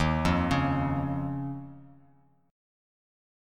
D#7sus2 chord